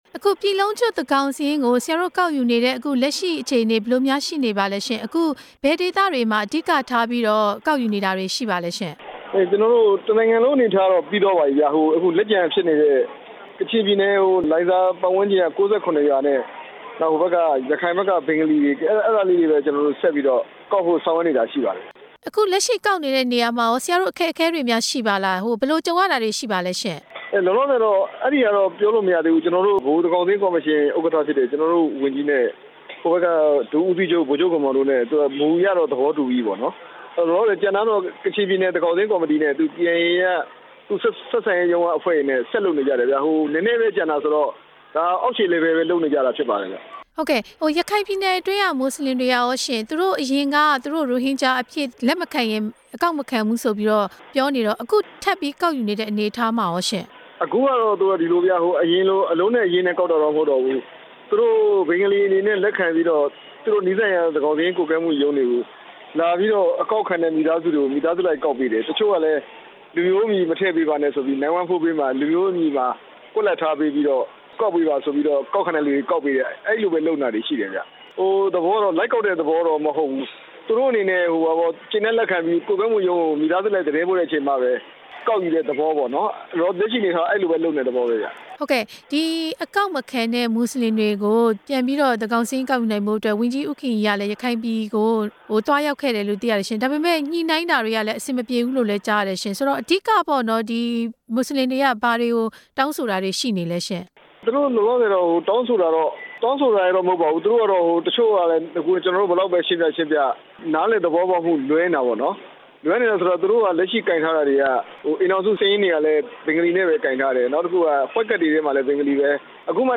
လ.ဝ.က ညွှန်ကြားရေးမှူးးချုပ် ဦးမြင့်ကြိုင် နဲ့ ဆက်သွယ်မေးမြန်းချက်